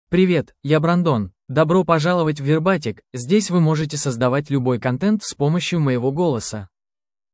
MaleRussian (Russia)
Brandon — Male Russian AI voice
Voice sample